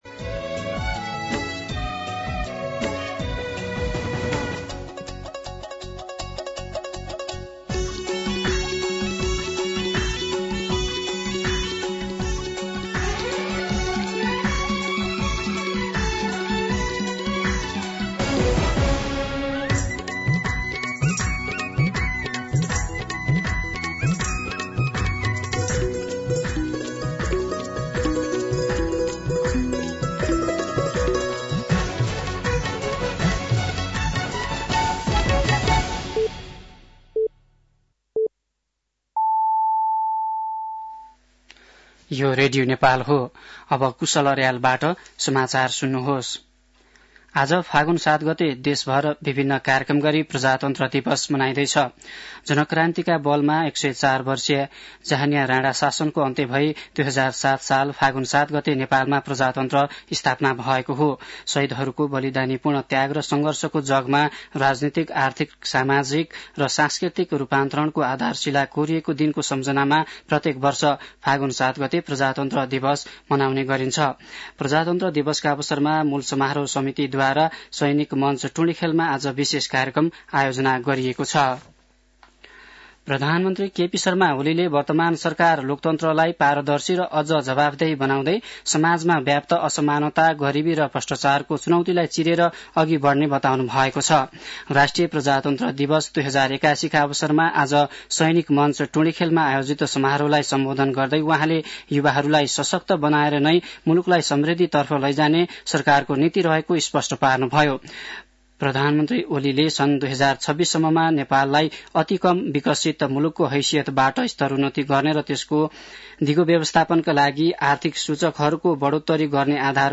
दिउँसो १ बजेको नेपाली समाचार : ८ फागुन , २०८१
1-pm-Nepali-News-11-07.mp3